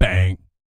BS BANG 04.wav